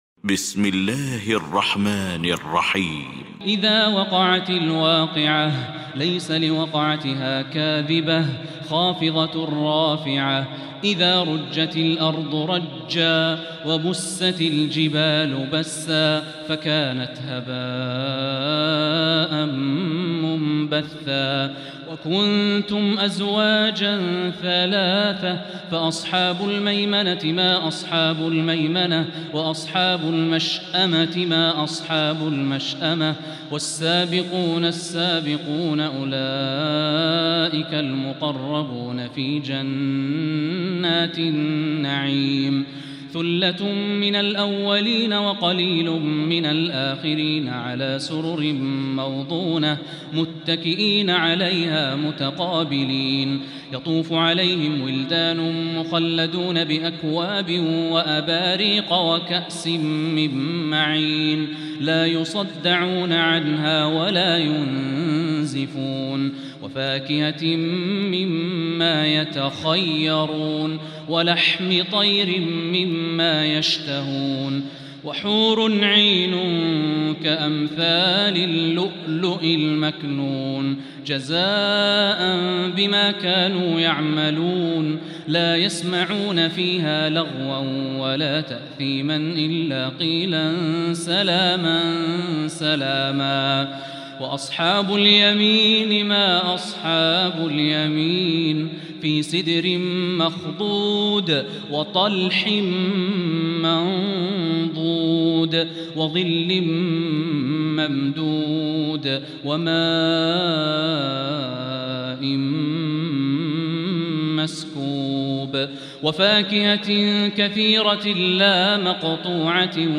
سورة الواقعة | تراويح الحرم المكي عام 1445هـ